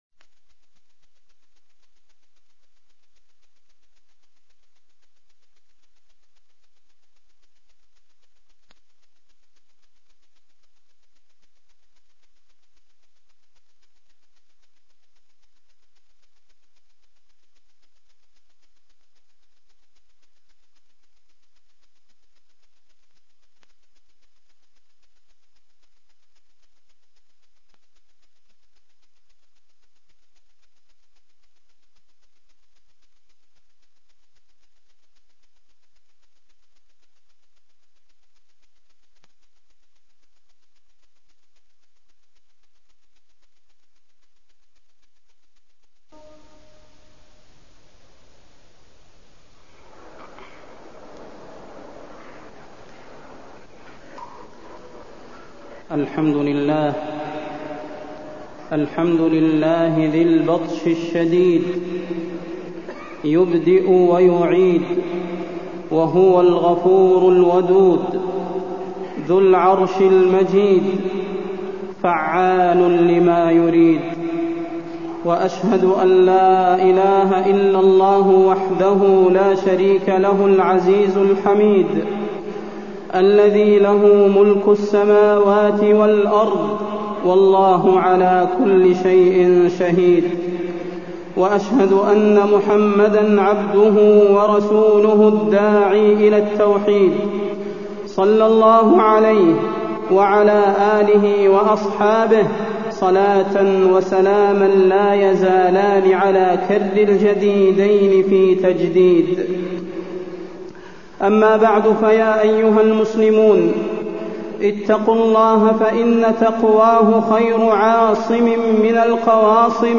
فضيلة الشيخ د. صلاح بن محمد البدير
تاريخ النشر ٢٥ محرم ١٤٢٤ هـ المكان: المسجد النبوي الشيخ: فضيلة الشيخ د. صلاح بن محمد البدير فضيلة الشيخ د. صلاح بن محمد البدير التوكل على الله والثبات على الدين